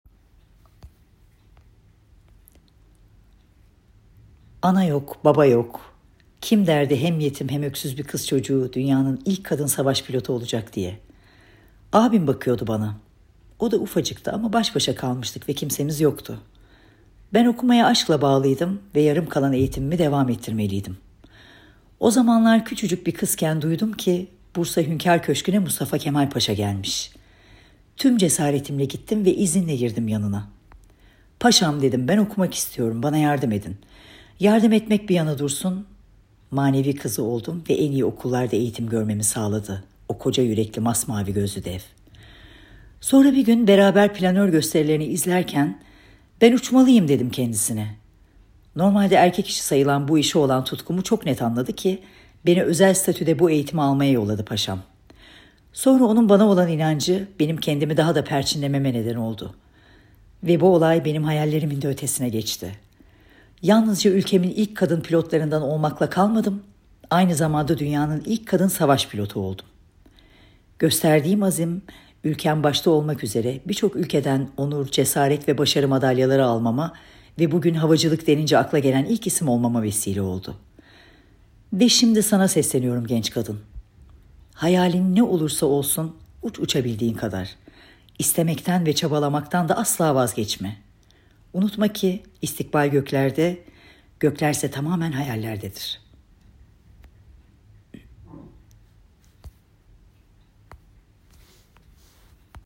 Seslendiren: Hande Ataizi ( Oyuncu)